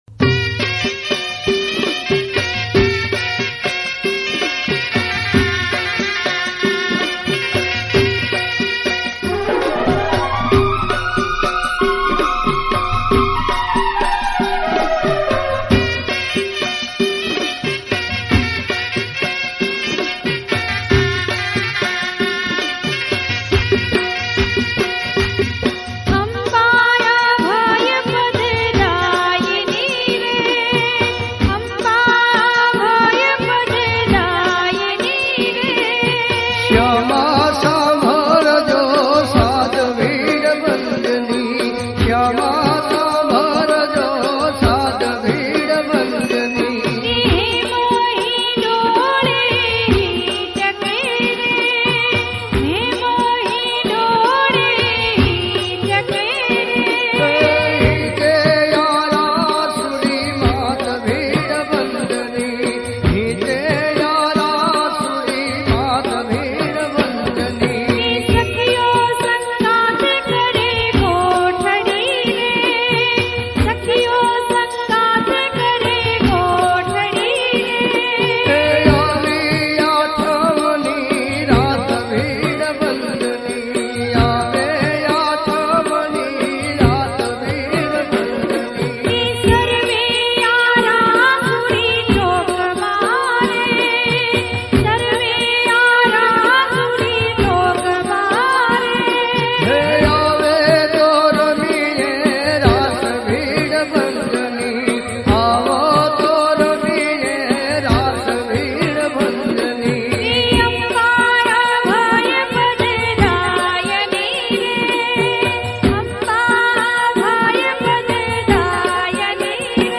ગીત સંગીત ગરબા - Garba
Traditional Hit Navratri Garba Song.